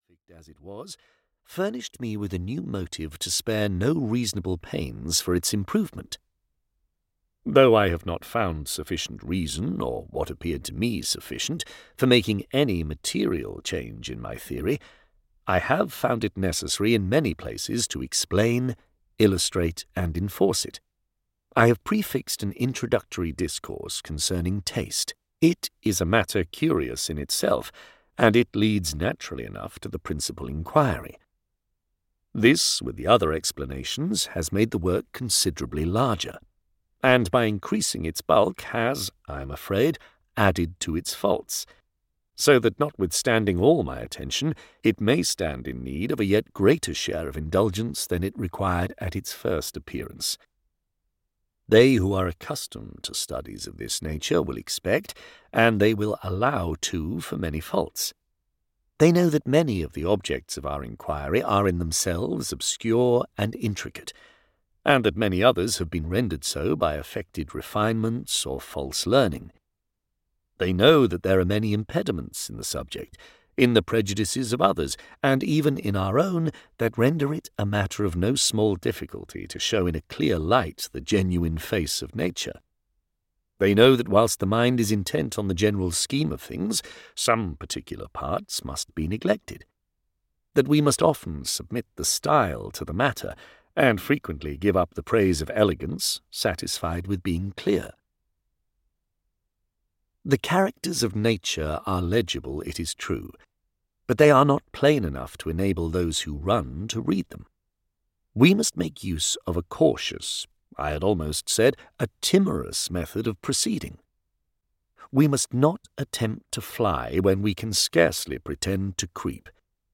A Philosophical Enquiry into the Sublime and Beautiful (EN) audiokniha
Ukázka z knihy